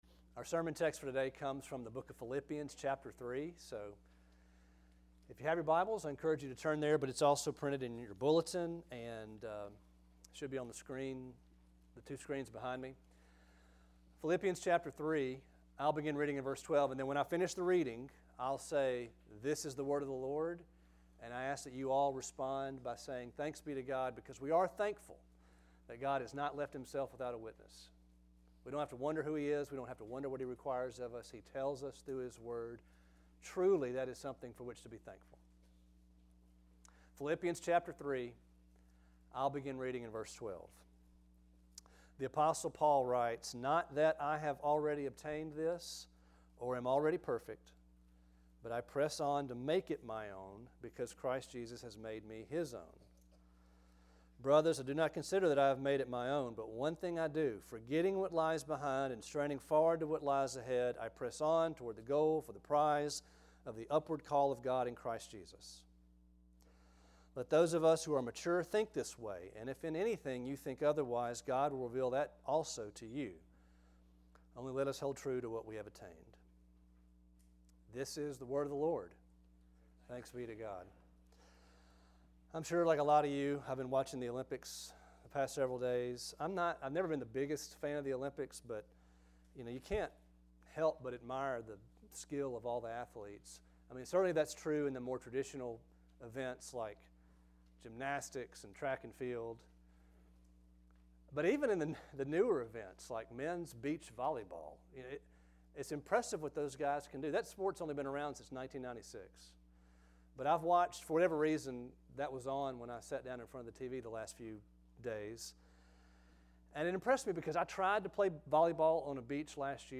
Sermons | Grace Bible Church of Oxford